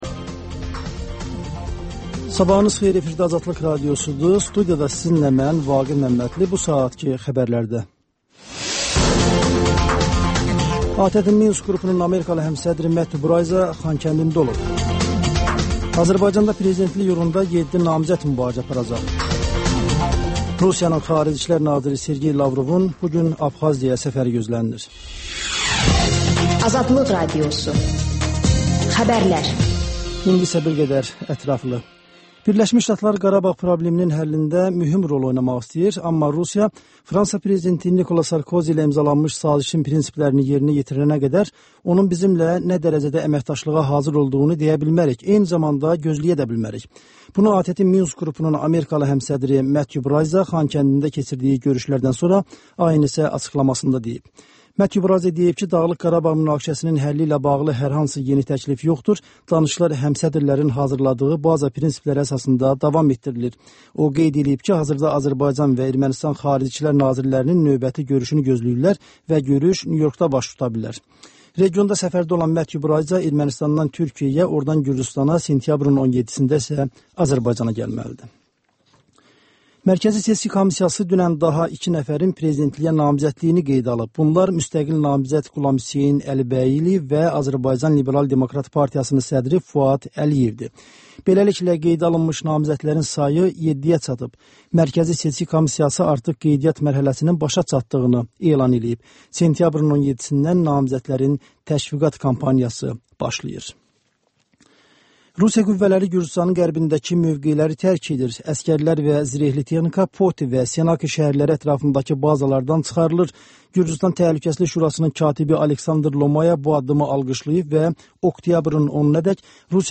Xəbərlər, QAFQAZ QOVŞAĞI: «Azadlıq» Radiosunun Azərbaycan, Ermənistan və Gürcüstan redaksiyalarının müştərək layihəsi, sonda QAYNAR XƏTT: Dinləyici şikayətləri əsasında hazırlanmış veriliş